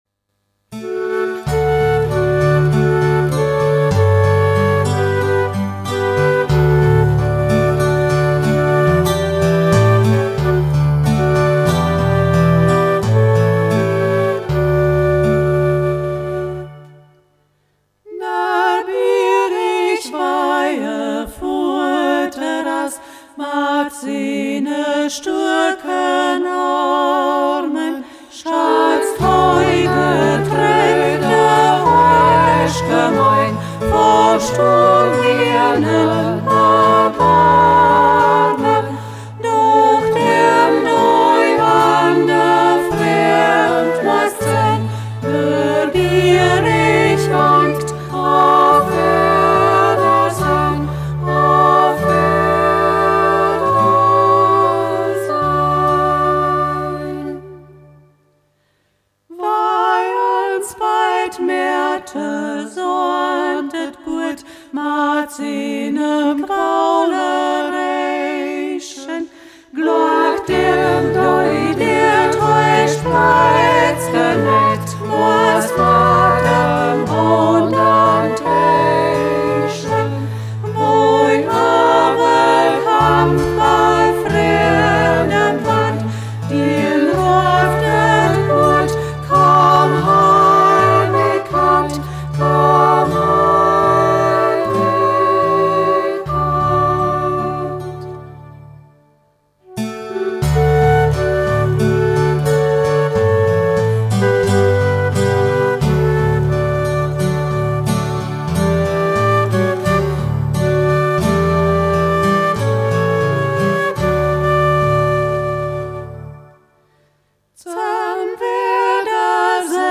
Ortsmundart: Zeiden